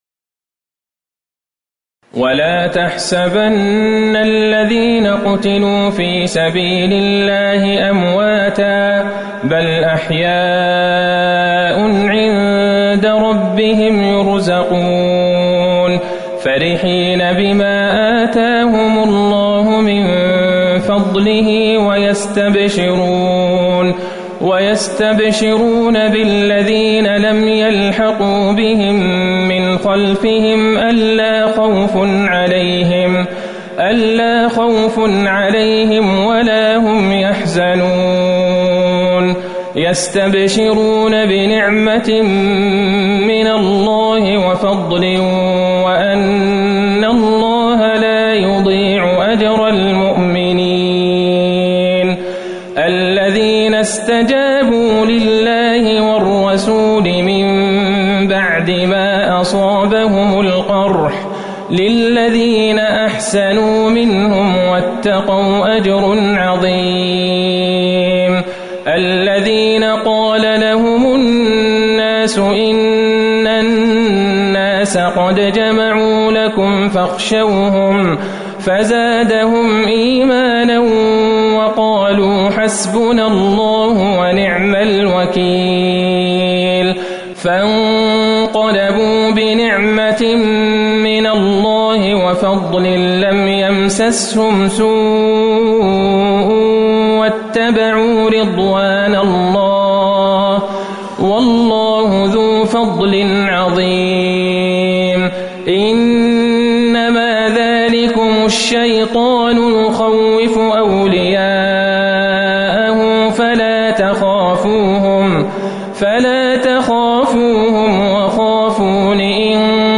صلاة العشاء ١٤٤١/١/٩ من سورة آل عمران | Isha prayer from Surat Aal-E-Imran > 1441 🕌 > الفروض - تلاوات الحرمين